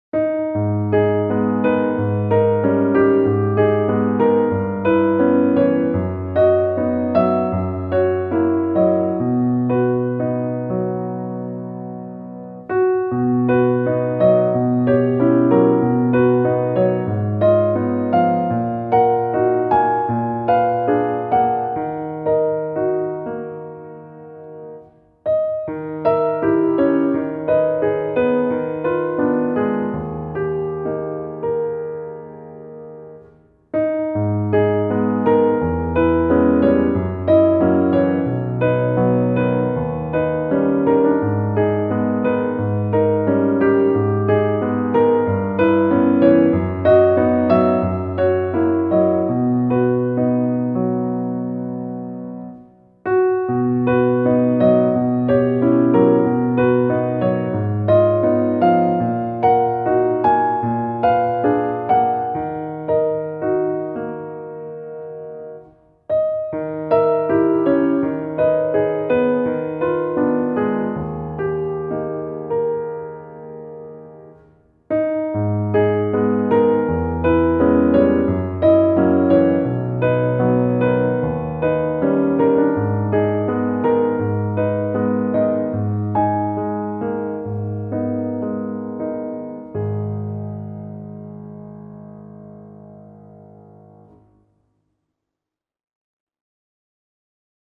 ♪サウンドプログラマ制作の高品質クラシックピアノ。